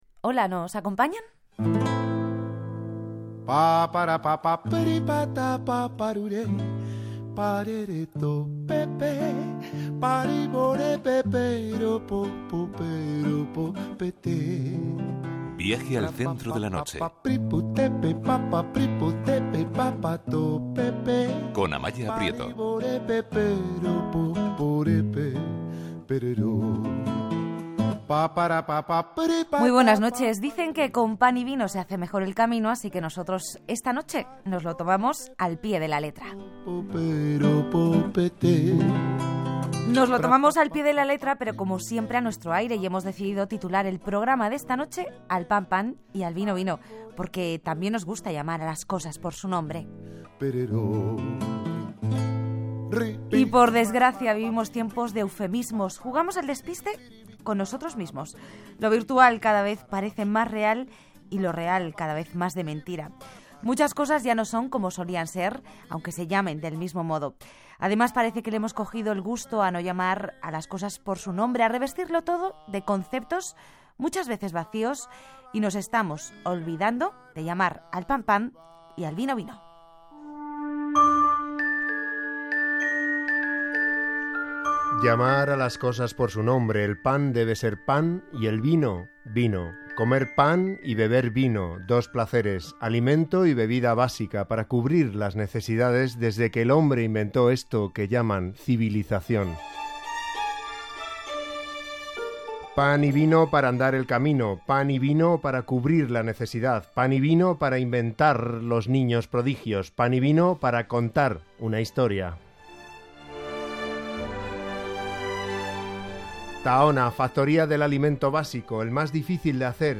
En los estudios de RAC1 Participación en Viaje al centro de la noche sobre el uso y abuso de los eufemismos . Radio Nacional de España.